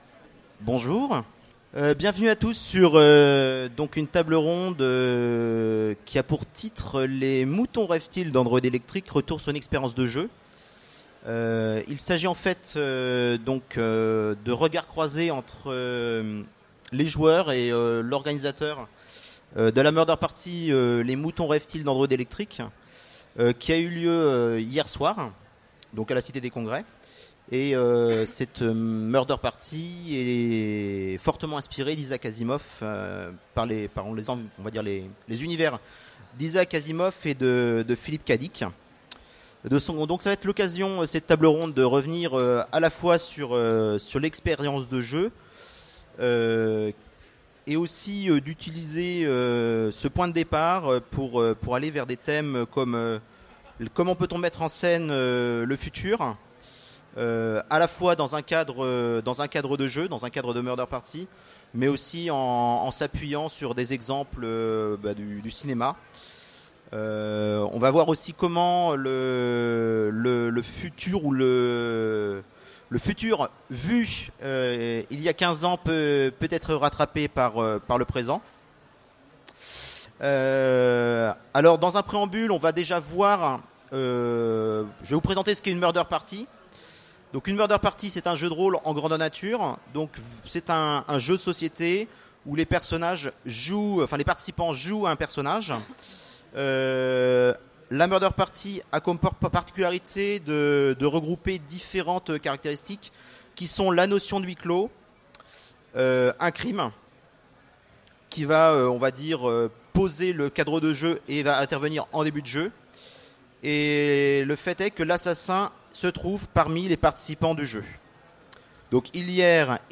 - le 31/10/2017 Partager Commenter Utopiales 2015 : Conférence Les moutons rêvent-ils d’androïdes électriques ?